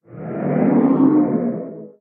guardian_idle1.ogg